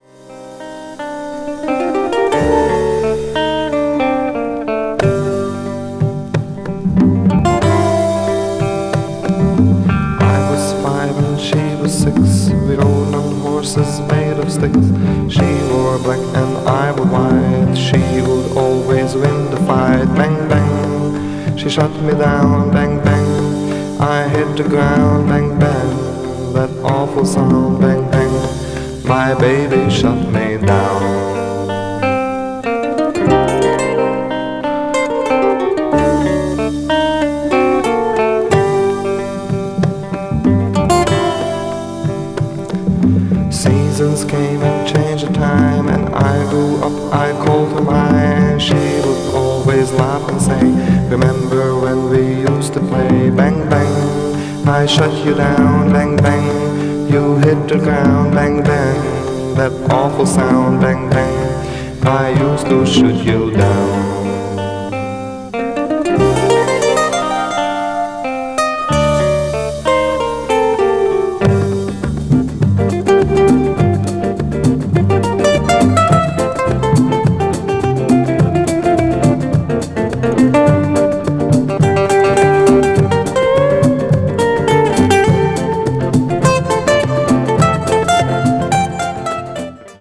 Mono. coated gatefold sleeve.